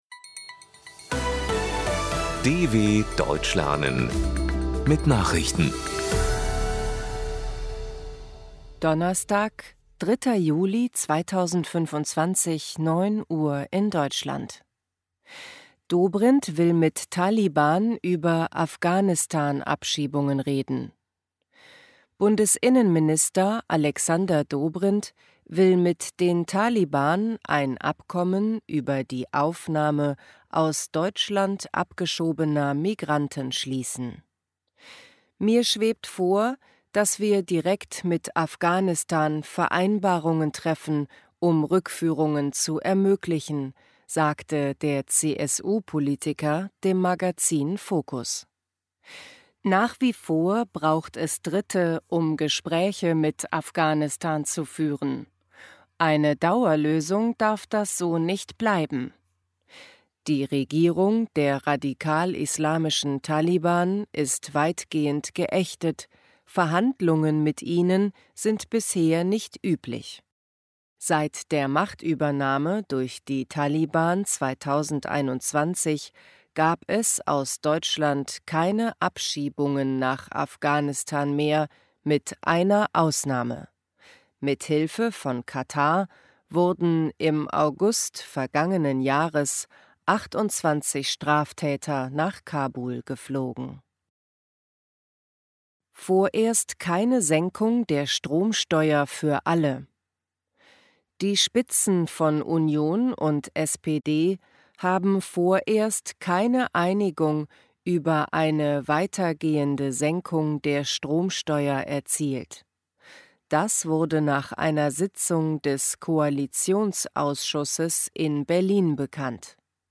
Langsam Gesprochene Nachrichten | Audios | DW Deutsch lernen
03.07.2025 – Langsam Gesprochene Nachrichten